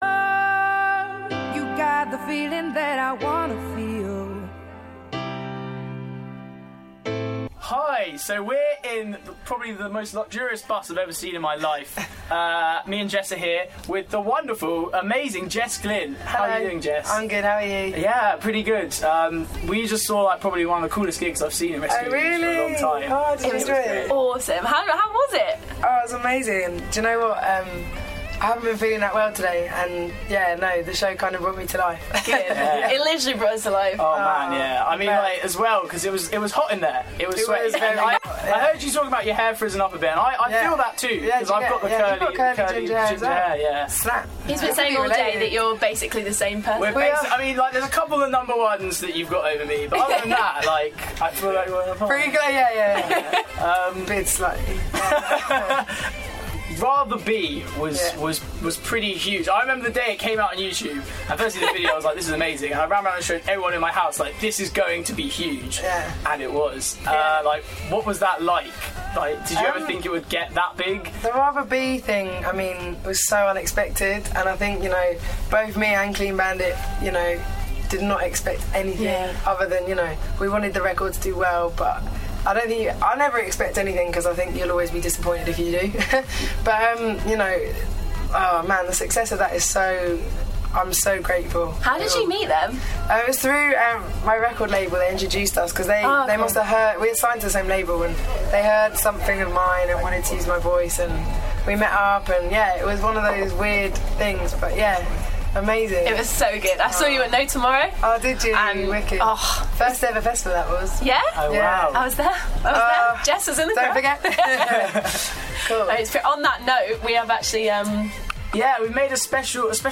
The Glynneterview